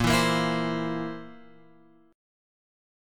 A# Suspended 2nd Flat 5th